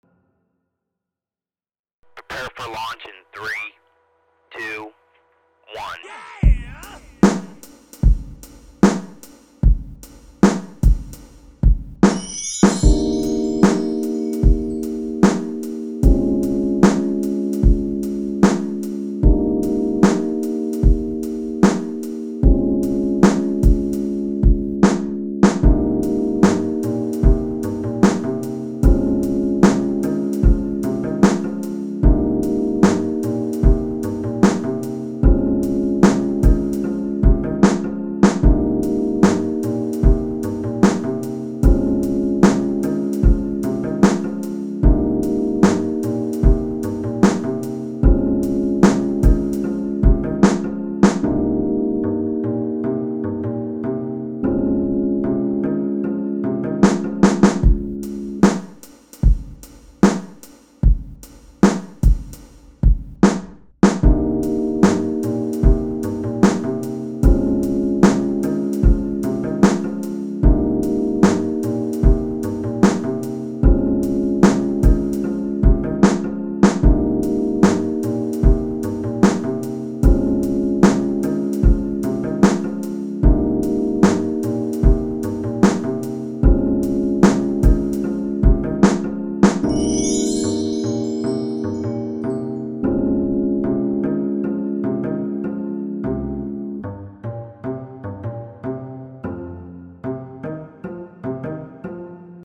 3 Thumb Up 미디어 듣기 재즈풍으로 간단히 만들어 봤습니다 랩 섞으면 어?떨까요? 3 Scrap This!